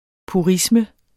Udtale [ puˈʁismə ]